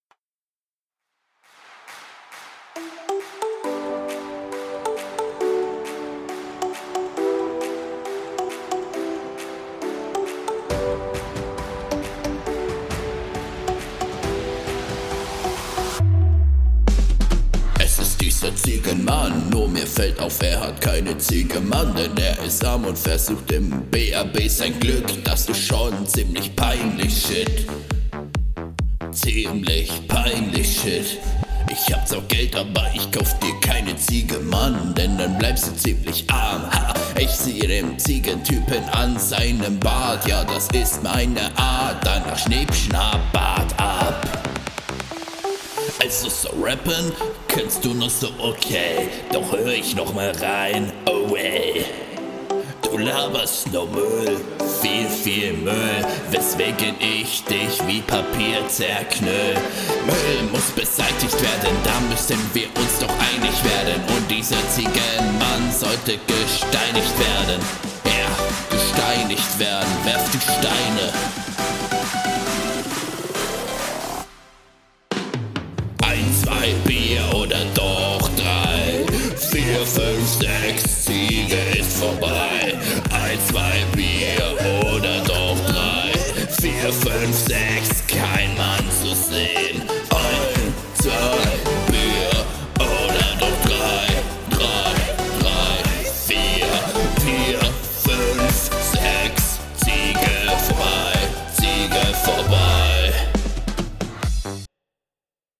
Beat ist absolut cool.
Richtiger Rap ist es nicht wirklich.